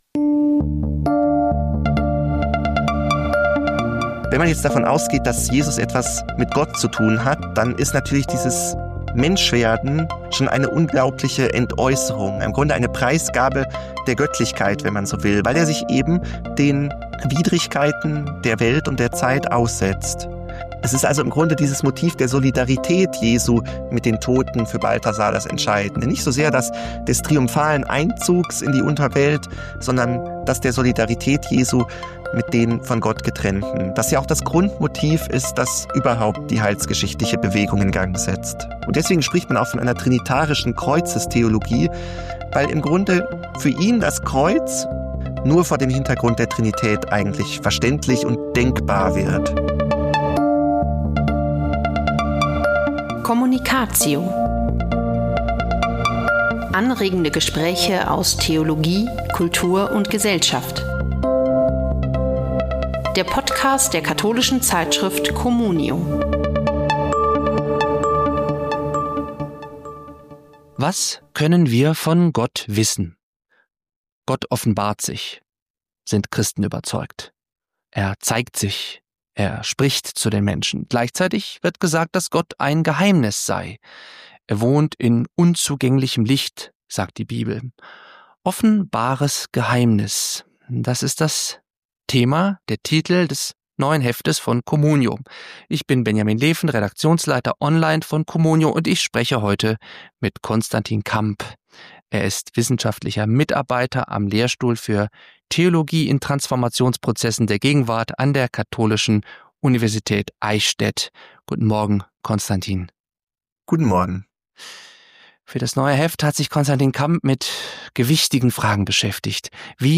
Beschreibung vor 7 Monaten Für COMMUNIO-Gründer Hans Urs von Balthasar ist das Kreuz der Höhepunkt einer Bewegung der göttlichen Selbstentäußerung. Balthasar ist überzeugt: Der allmächtige Gott offenbart sich in der Ohnmacht des Kreuzes als die reine Liebe. Im Gespräch